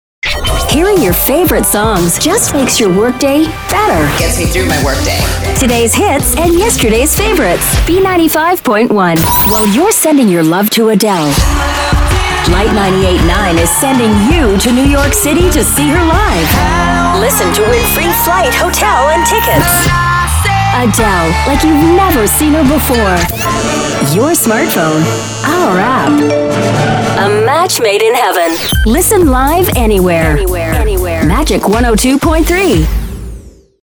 Englisch (US) Synchronsprecher